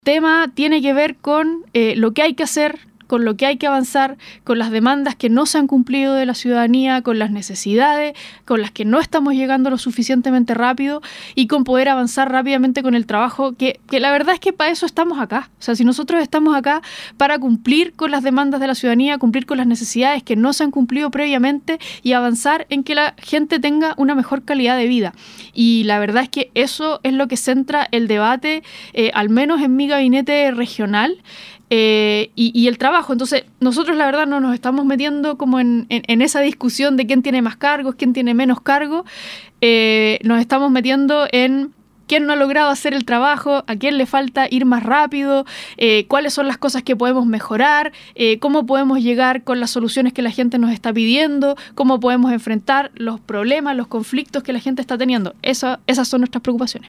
En conversación con Nuestra Pauta, la autoridad política recalcó que a nivel regional «ha sido un año complejo» por distintas razones, pero que «me enorgullece el trabajo que hemos hecho, porque siento que hemos avanzado en las cosas que a la gente le preocupan realmente».